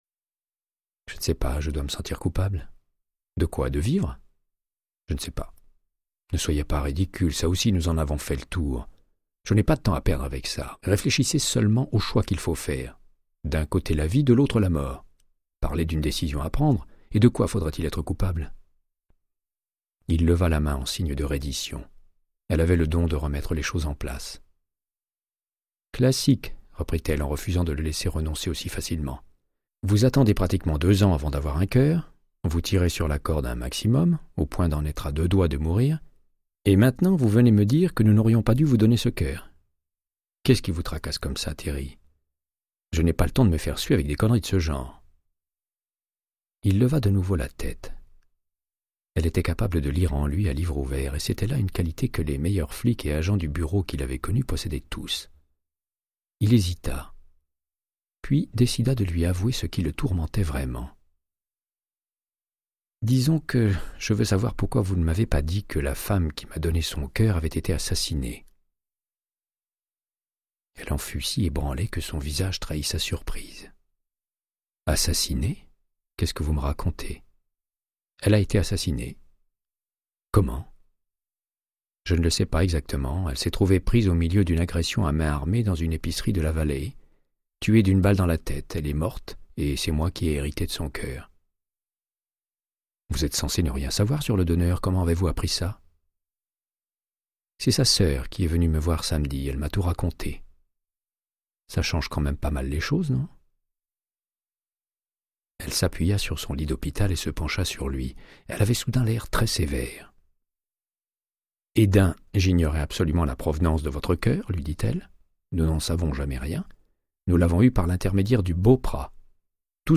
Audiobook = Créance de sang, de Michael Connellly - 06